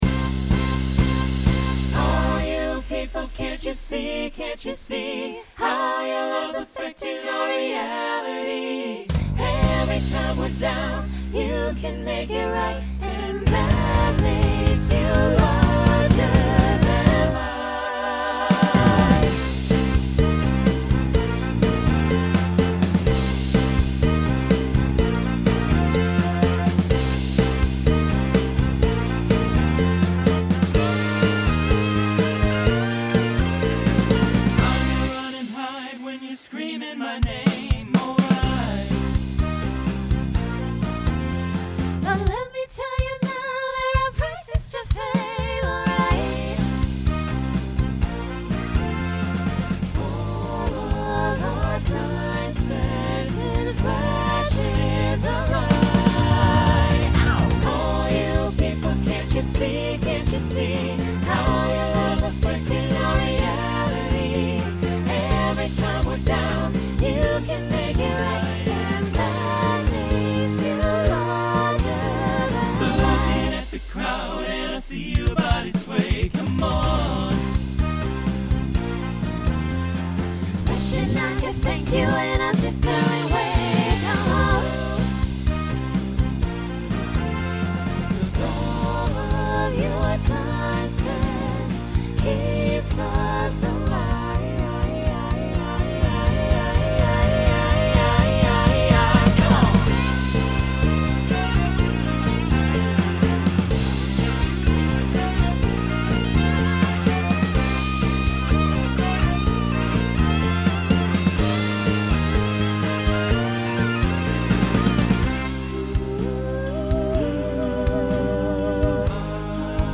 New TTB Voicing for 2023.